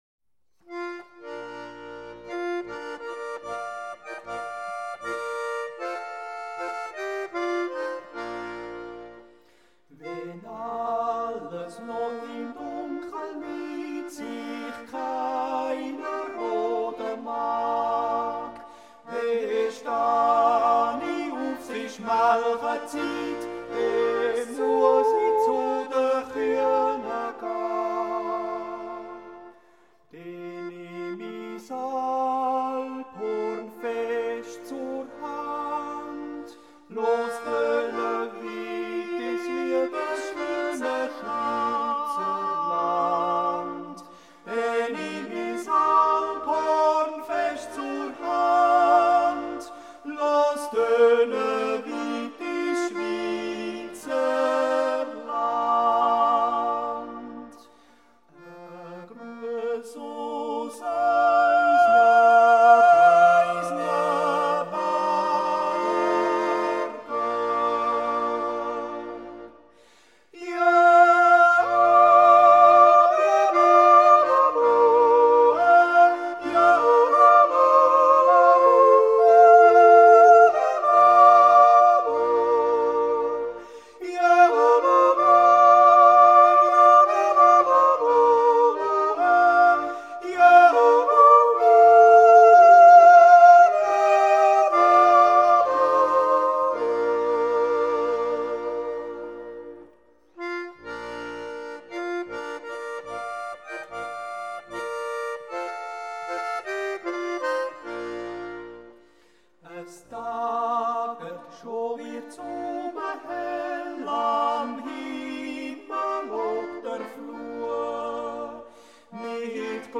A Swiss National Yodeling Festival
Canton Basel-Land